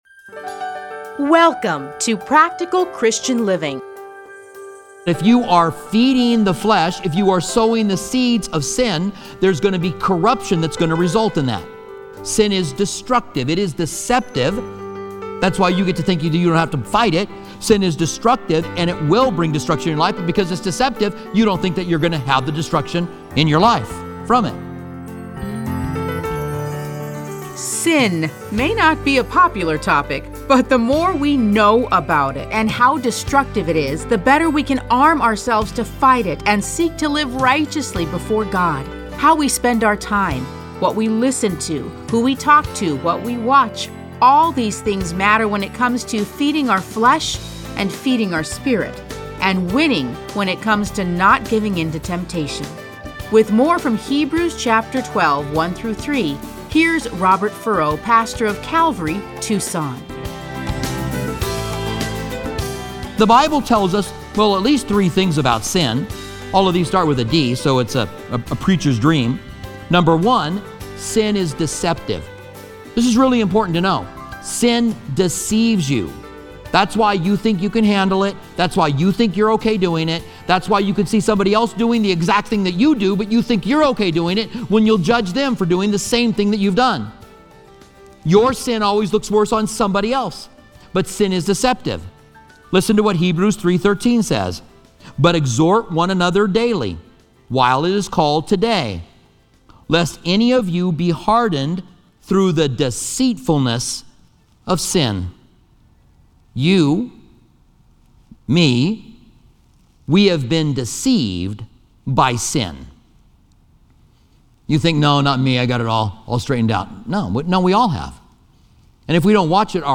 Listen to a teaching from Hebrews 12:1-3.